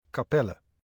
Kapelle (Dutch pronunciation: [kaːˈpɛlə]